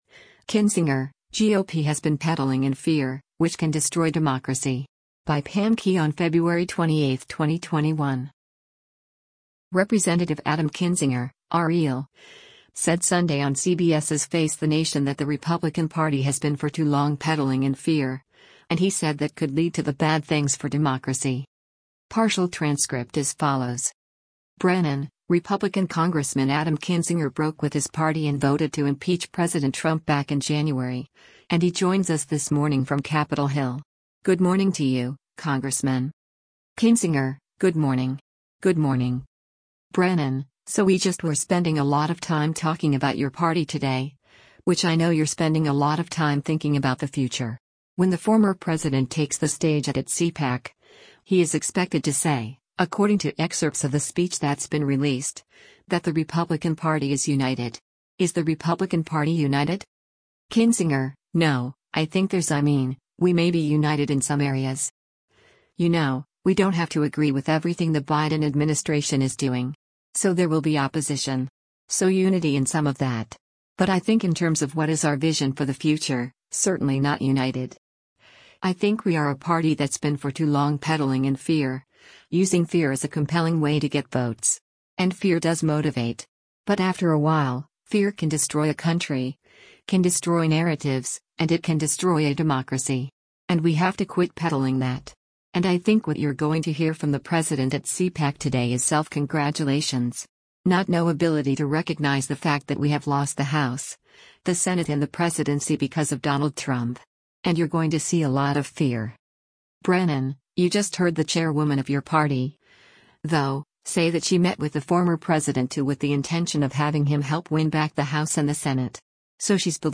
Representative Adam Kinzinger (R-IL) said Sunday on CBS’s “Face the Nation” that the Republican Party has “been for too long peddling in fear,” and he said that could lead to the bad things for democracy.